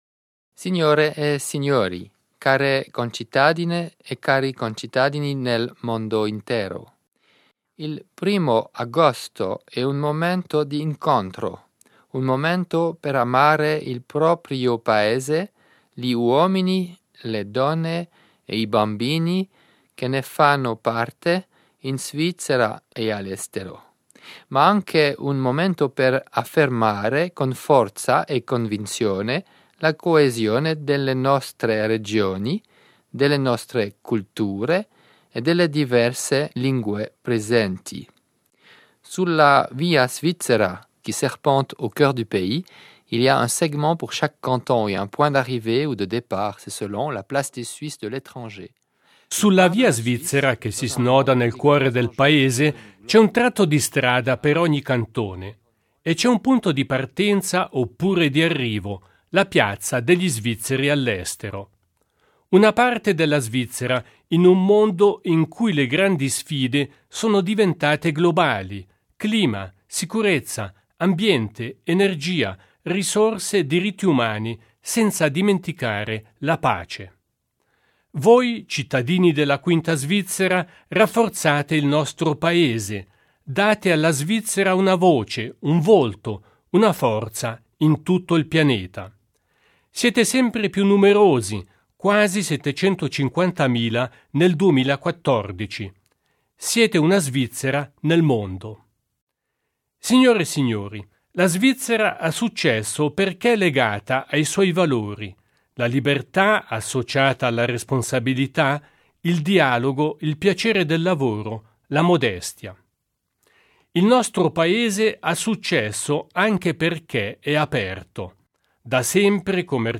Messaggio del presidente della Confederazione Didier Burkhalter agli Svizzeri all’estero in occasione della Festa nazionale.